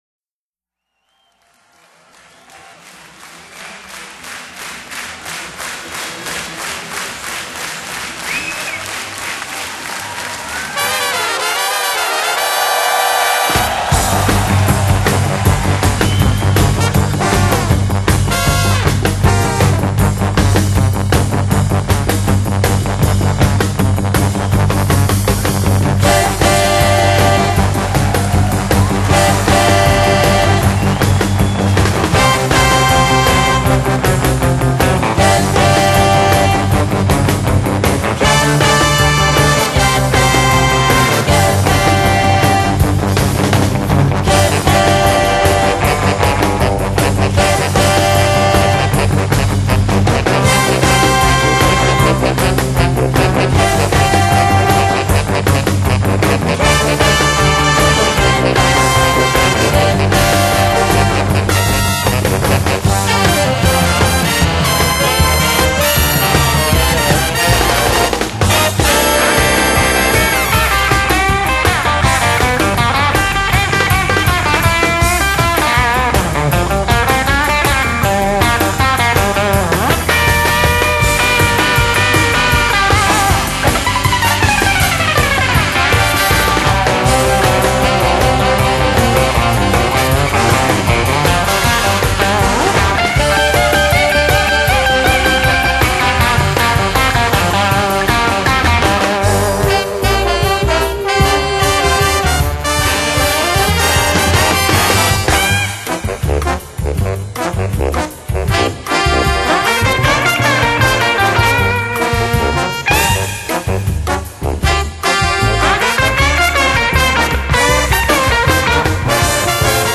（2009年日本巡迴演唱會–錄製的紀念專輯）